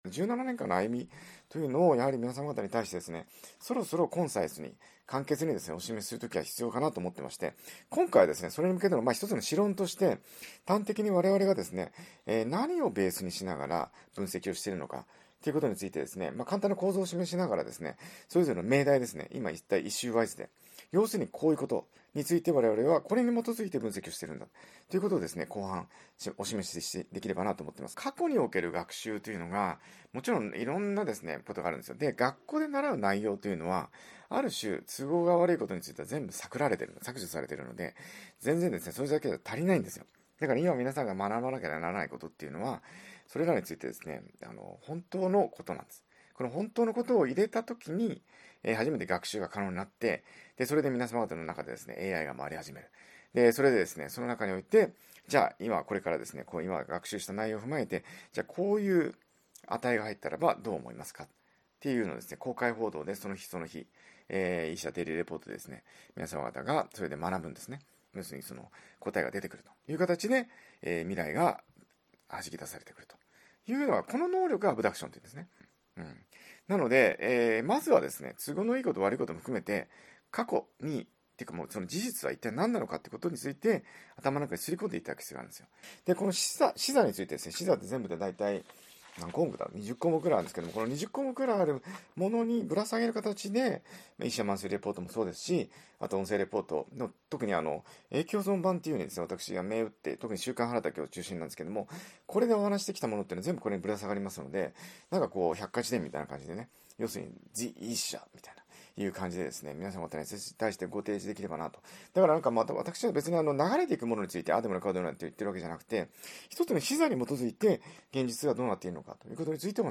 音声レポート140分。